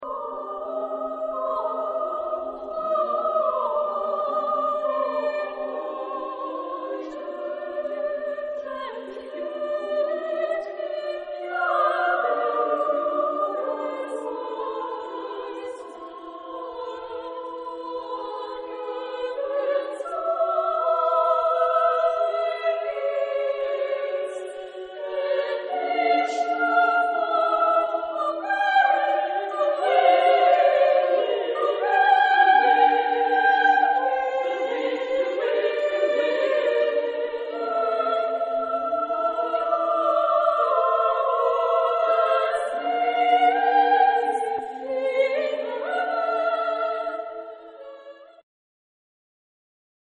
Genre-Style-Forme : contemporain ; Chanson ; Profane
Caractère de la pièce : cantabile ; reposant ; lent
Type de choeur : SATB  (4 voix mixtes )
Tonalité : post-tonal